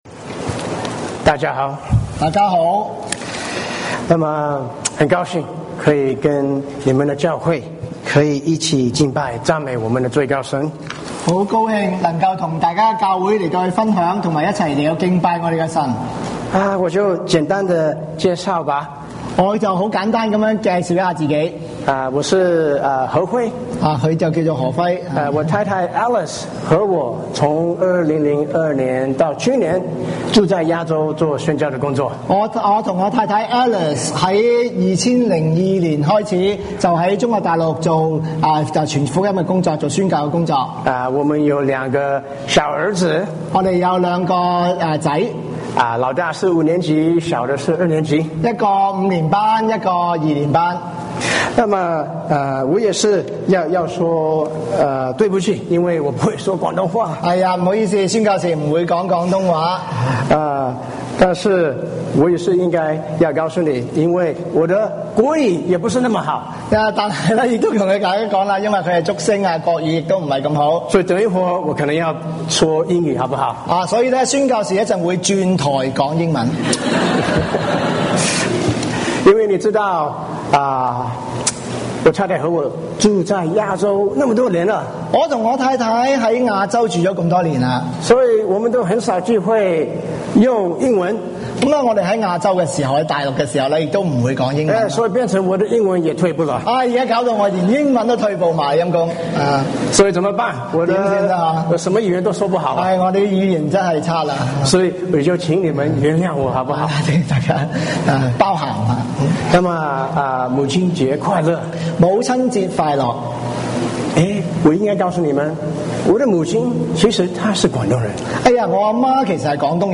華埠粵語三堂